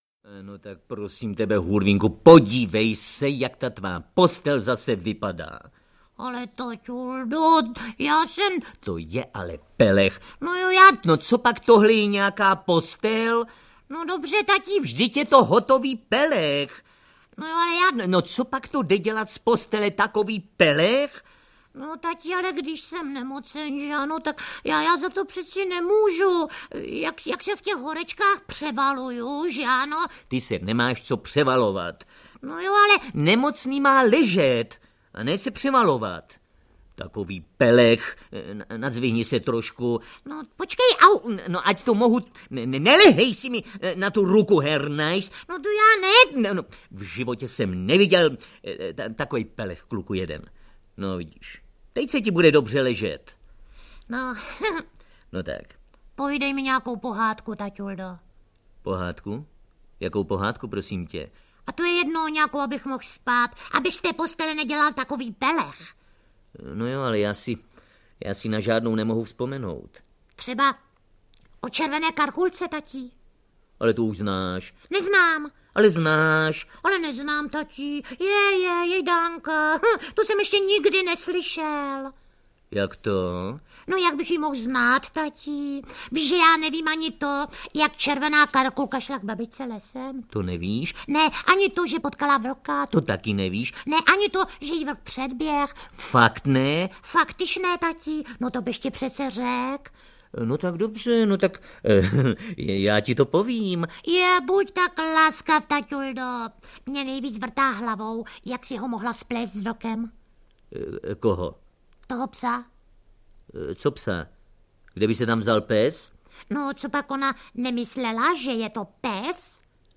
Hurvínek a drak - jubilejní edice audiokniha
Ukázka z knihy